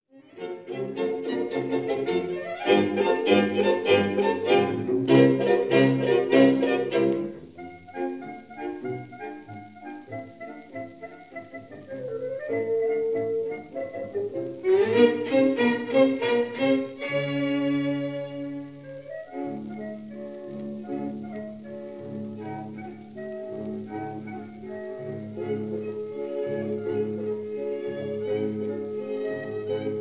clarinet
bassoon
horn
violins
viola
cello
double-bass
Recorded in Société suisse de radiodiffusion
studio, Geneva in July 1948